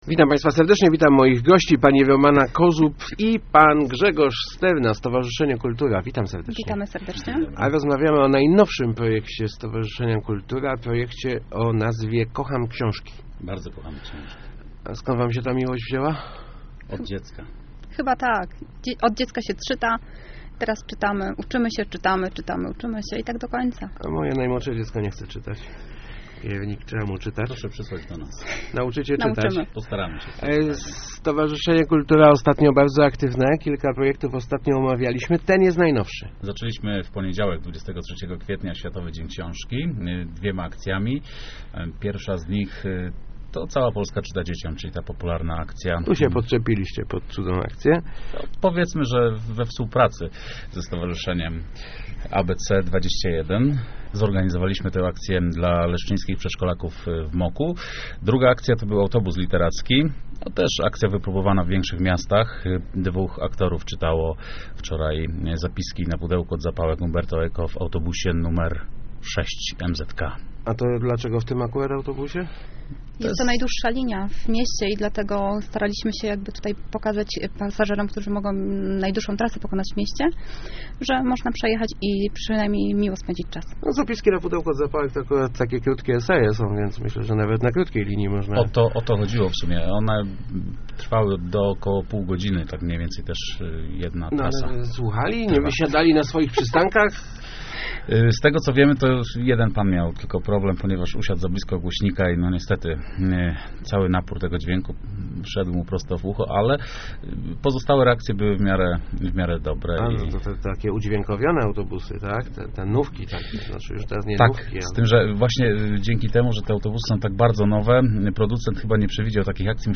opowiadali o nich w Rozmowach Elki